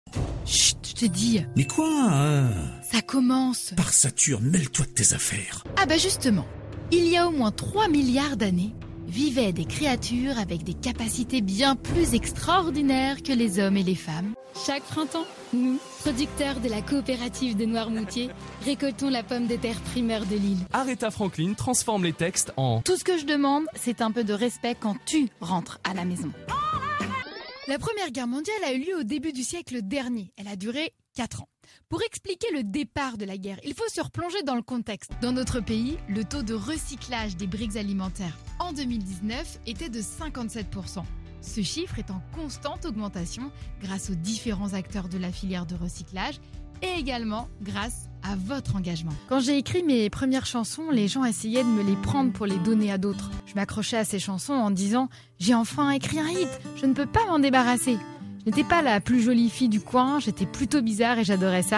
Demo voix OFF
- Mezzo-soprano